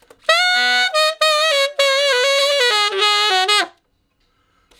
068 Ten Sax Straight (Ab) 06.wav